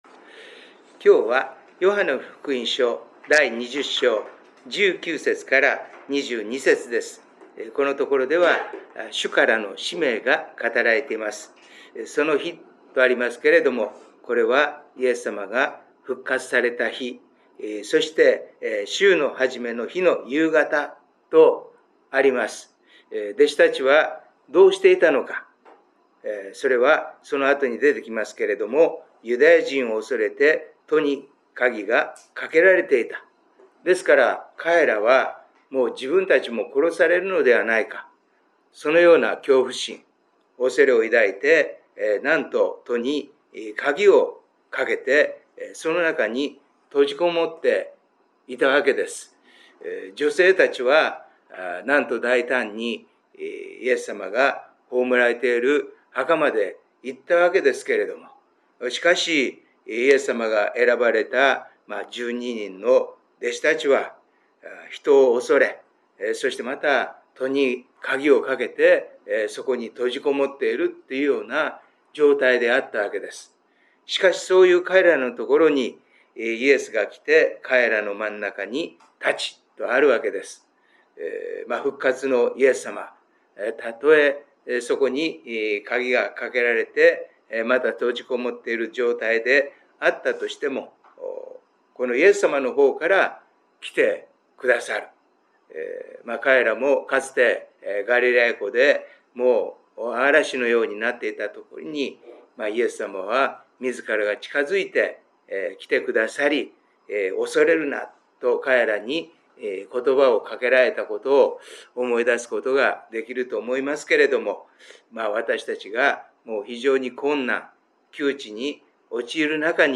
礼拝メッセージ「救いの道」│日本イエス・キリスト教団 柏 原 教 会